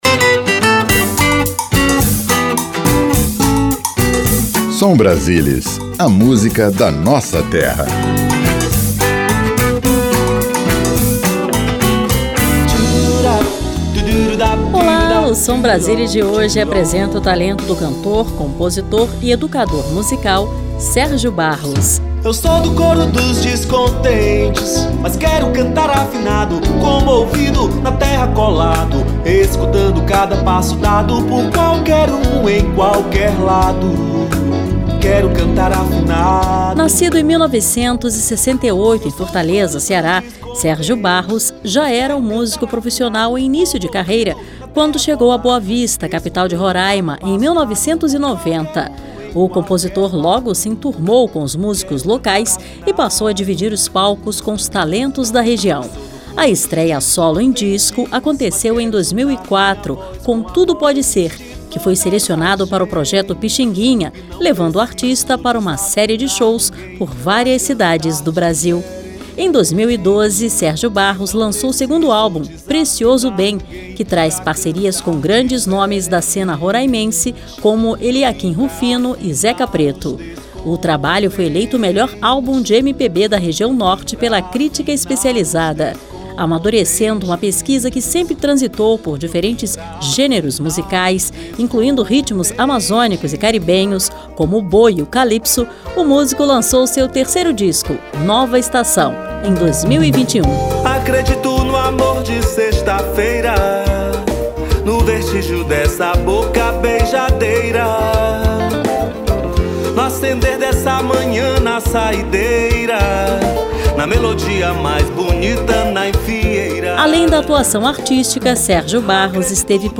Programete musical que apresenta artistas de cada estado da federação.